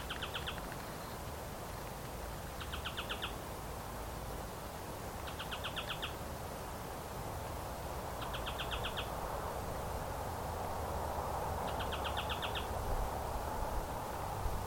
Ochre-flanked Tapaculo (Eugralla paradoxa)
150208_009-Churrin-Grande.mp3
Frecuentaban el Colihue. Rosa mosqueta. Ratamo y Retama, se los observo cercanos a un arroyo y a un camino de acceso a Villa Catedral.
Condition: Wild
Certainty: Photographed, Recorded vocal